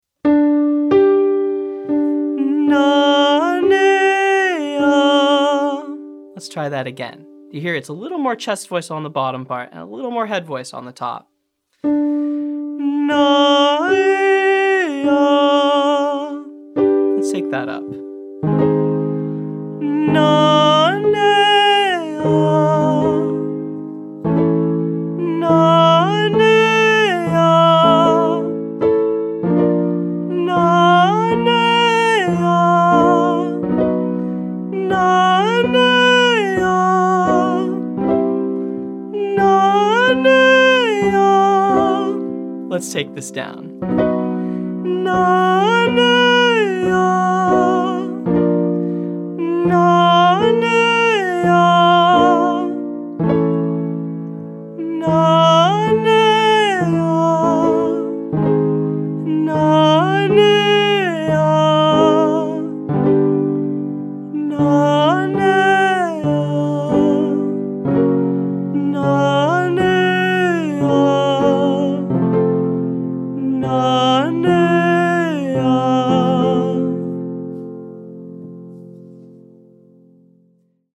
• Nuh-Nay-Uh  (1, 4,1)